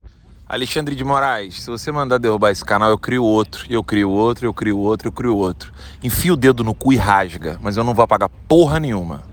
Allan dos Santos surta e ataca Alexandre de Moraes
O blogueiro Allan dos Santos soltou um áudio em seu canal do Telegram nesta sexta-feira (25) fazendo a única coisa que sabe fazer: atacar o ministro Alexandre de Moraes.